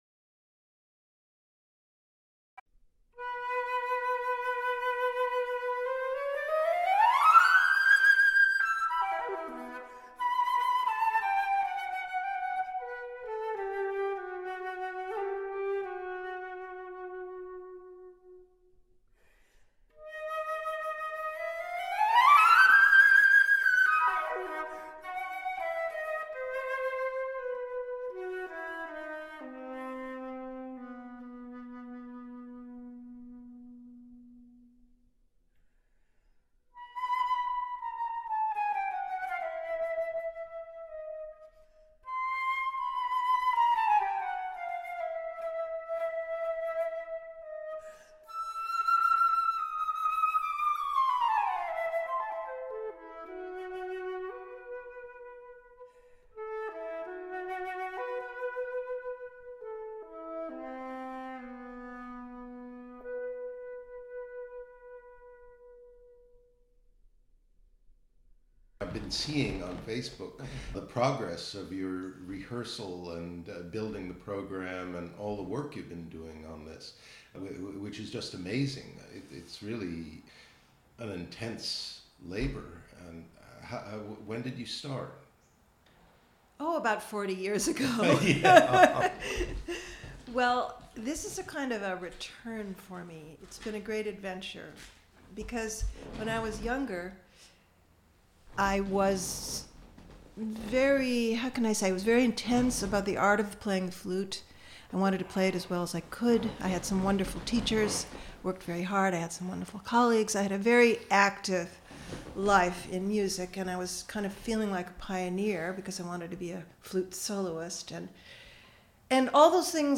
The interview is introduced and concluded with excerpts from Sidney Lanier's Wind Song, which you can hear in its entirety below.